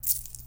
Money/Cash sound for a sci-fi game with spaceships
5_Coins.ogg